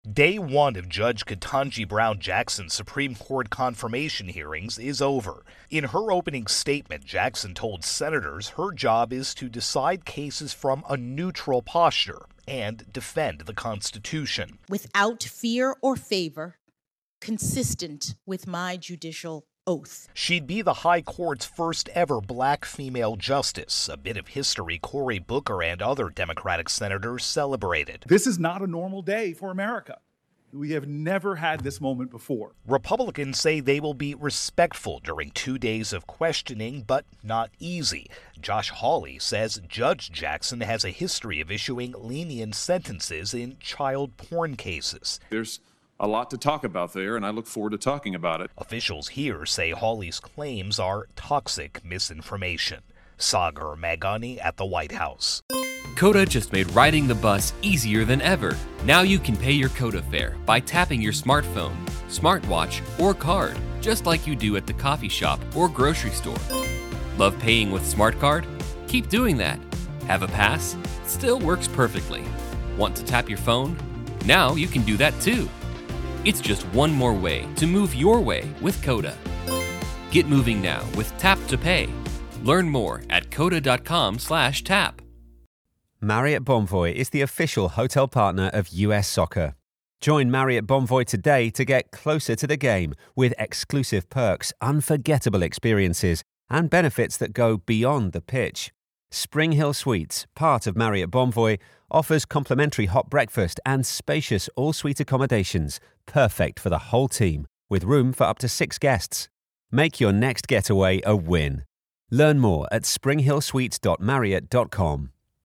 Supreme Court-Nomination intro and wrap.